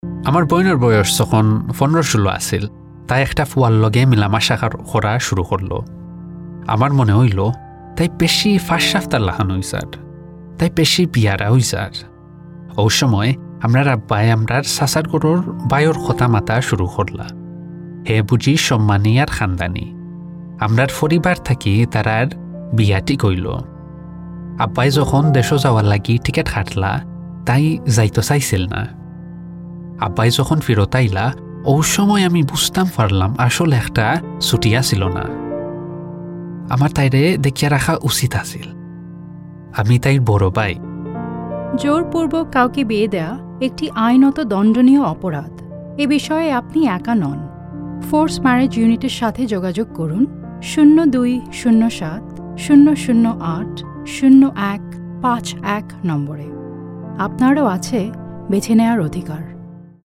Bengali, Male, 20s-30s
Bengali-Voicereel.mp3